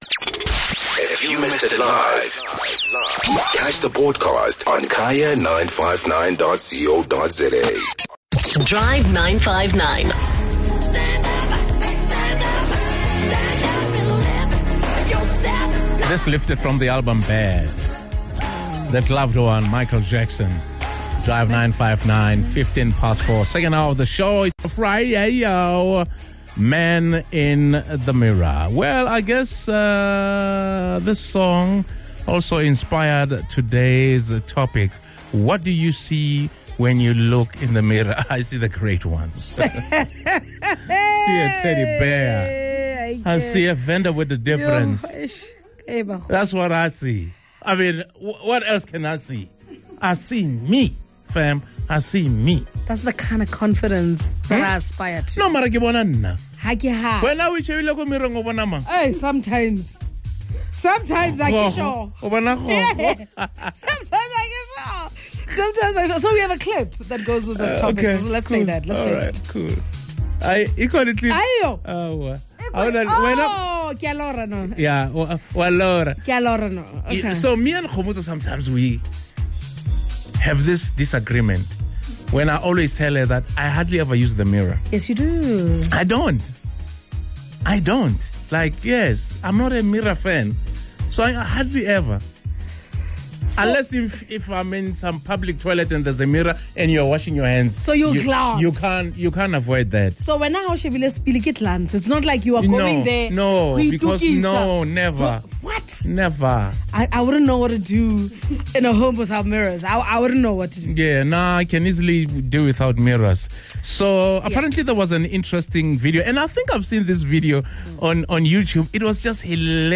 When you look in the mirror, what do you see? Take a listen to what our Drive 959 Team and our listeners had to say about themselves!